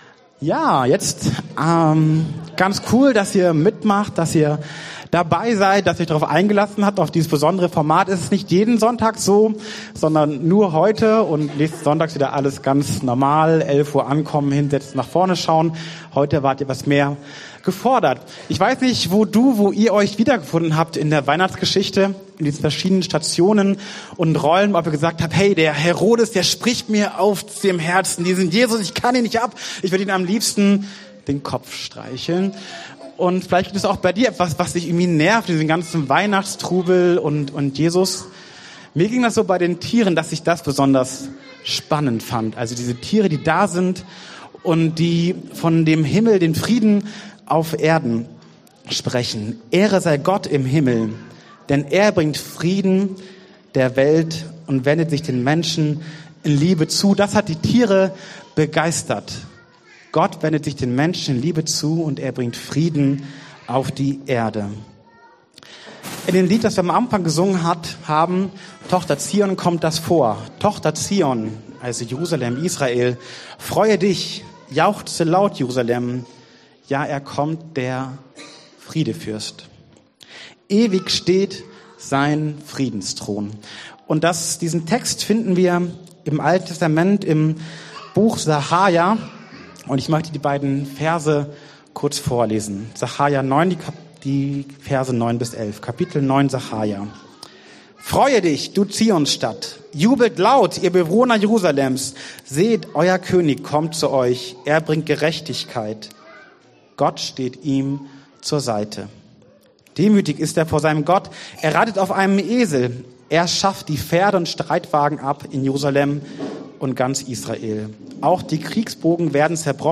Lebendige Hoffnung Dienstart: Predigt Themen